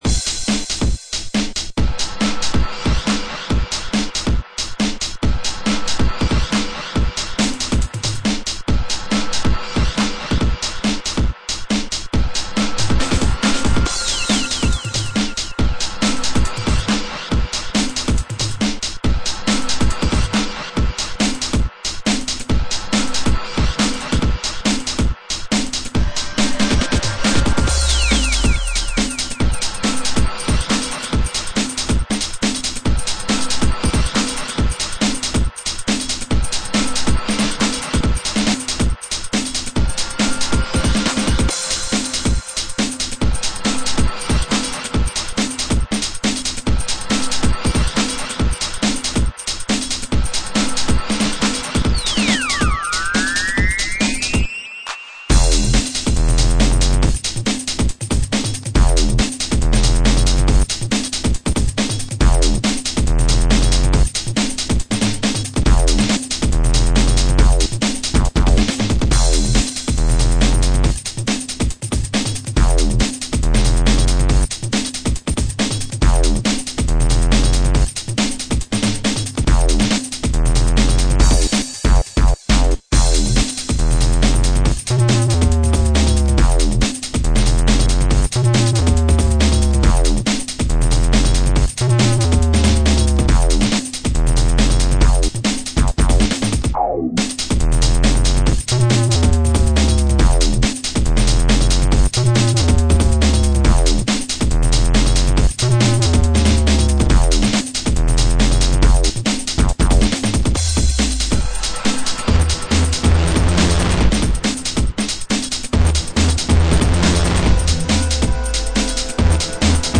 dance/electronic
UK-garage
Breaks & beats